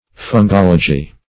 fungology - definition of fungology - synonyms, pronunciation, spelling from Free Dictionary
Fungology \Fun*gol"o*gy\